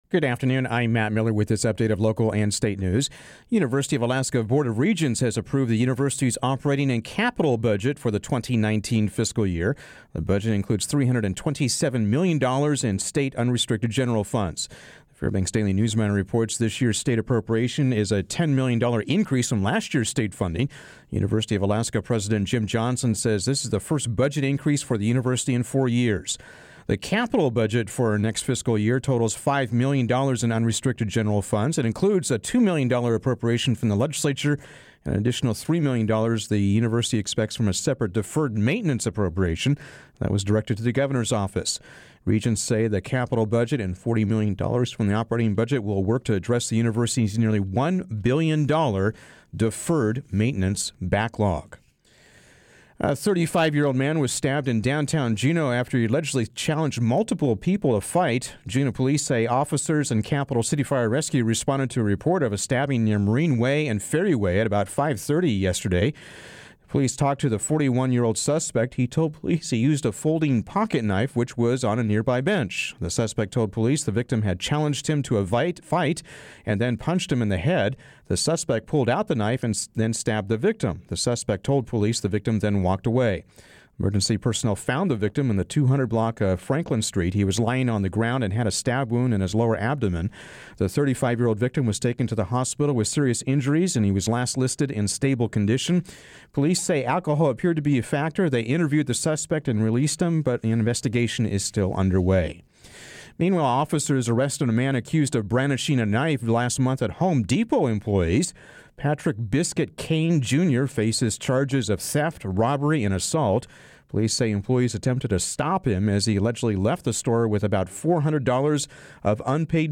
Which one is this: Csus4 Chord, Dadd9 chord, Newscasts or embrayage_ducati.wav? Newscasts